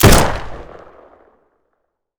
gun_rifle_shot_01.wav